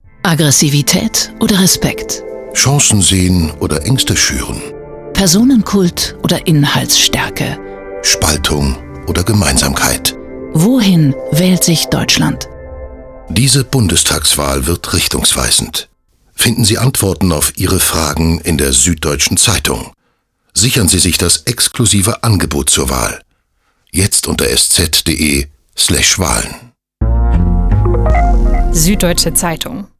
Schauspieler - Sprecher